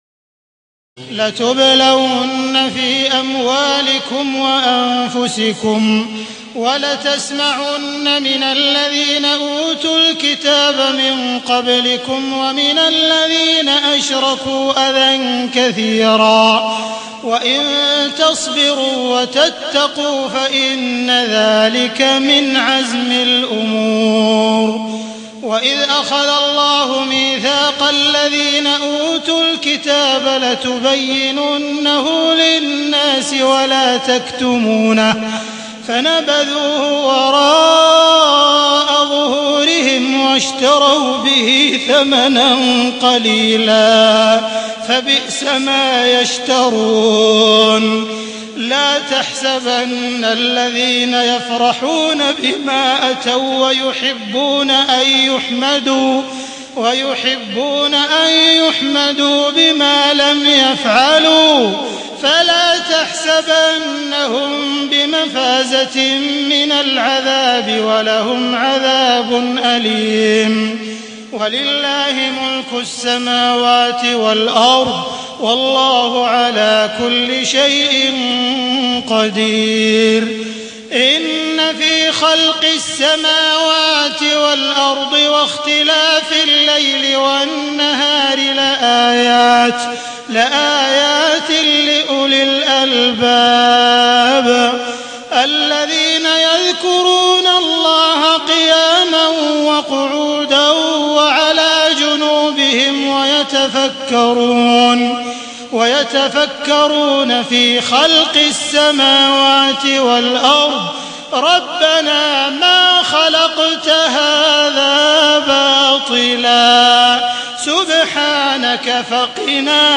تهجد ليلة 24 رمضان 1432هـ من سورتي آل عمران (186-200) و النساء (1-24) Tahajjud 24 st night Ramadan 1432H from Surah Aal-i-Imraan and An-Nisaa > تراويح الحرم المكي عام 1432 🕋 > التراويح - تلاوات الحرمين